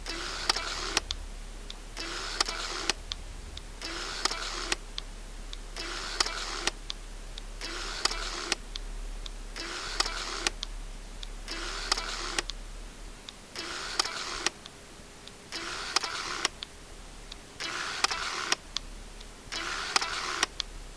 Nikon D800 fázis AF állításának hangja (MP3) élőkép módban.
nikon_af.mp3